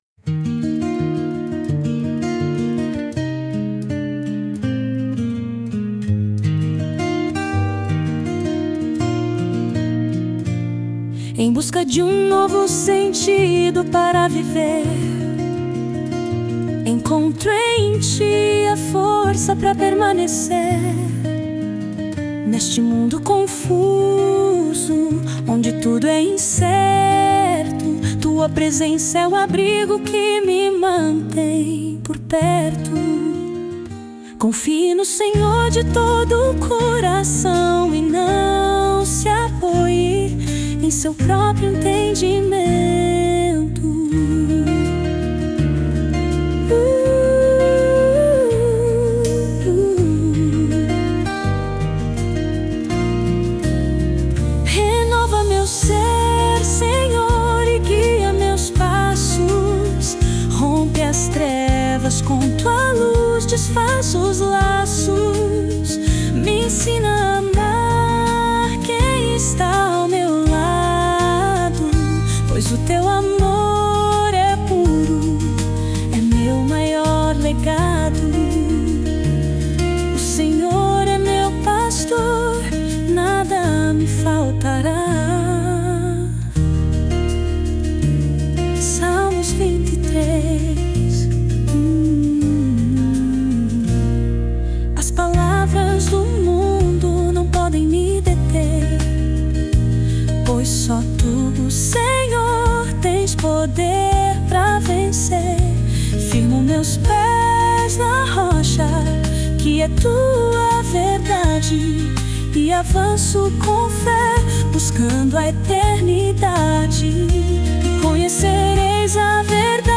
Baixar Música Grátis: Louvores com Inteligência Artificial!